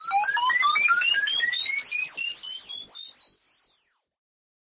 太空小说的声音效果 " 06744 火星代码丁
描述：科幻机器人火星叮
标签： 未来 密码 科幻 火星 机器人 无规 哔哔声 计算机 代码
声道立体声